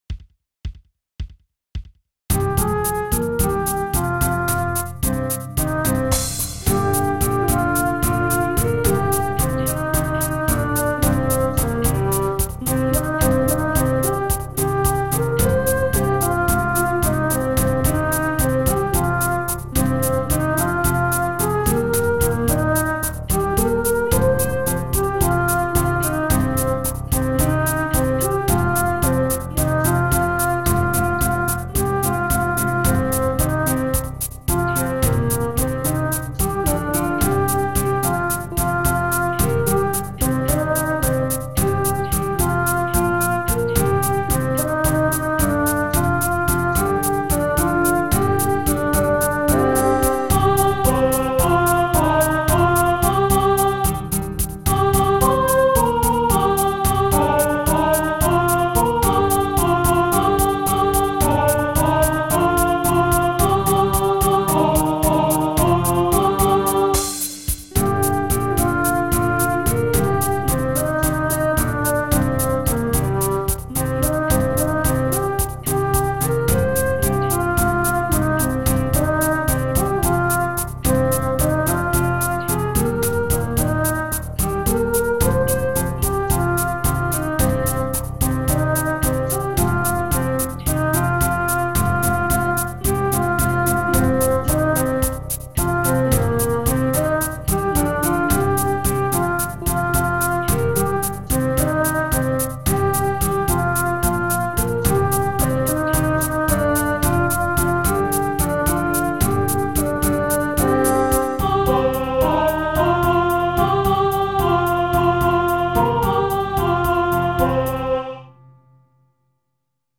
歌手　：　コンピューター